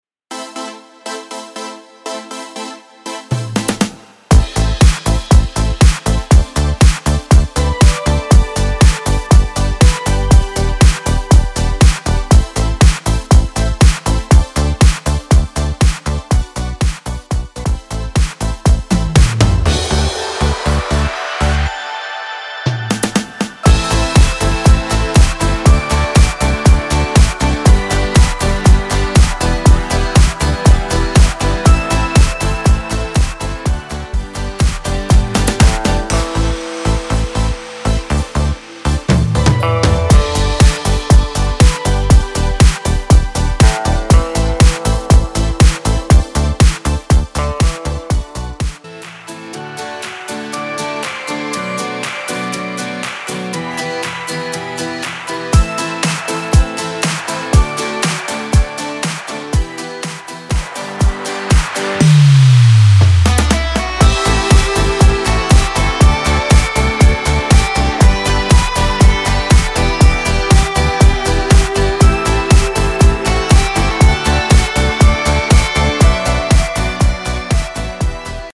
Tonacija: G-A-F (su bekais ir be)